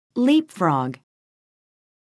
듣기반복듣기 미국